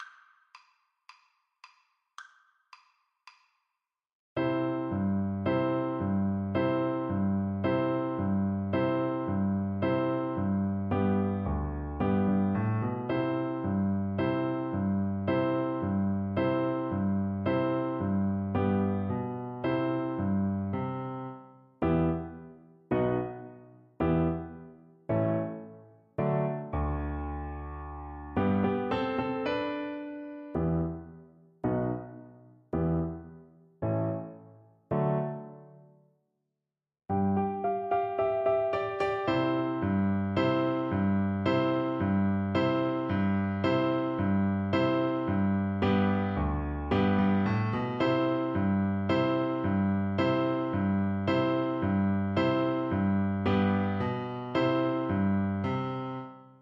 A great tune with a light syncopation.
4/4 (View more 4/4 Music)
Arrangement for French Horn and Piano
Vivo
Jazz (View more Jazz French Horn Music)